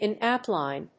アクセントin óutline